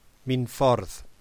To hear how to pronounce Minffordd, press play: